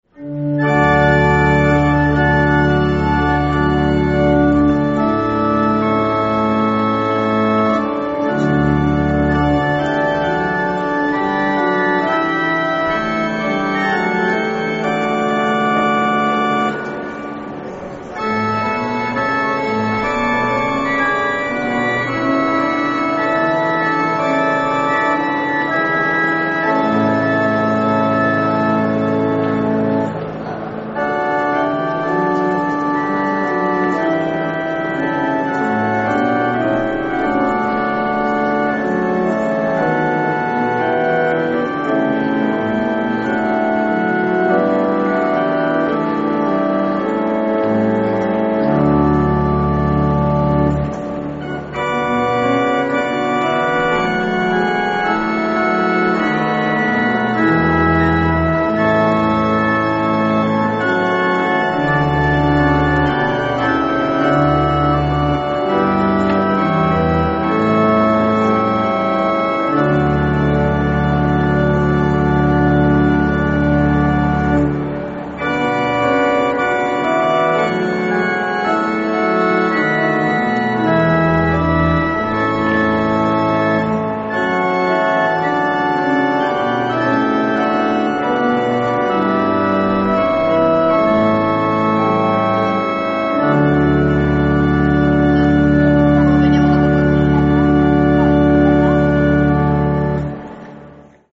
BENEDIZIONE E POSLUDIO ORGANISTICO FINALE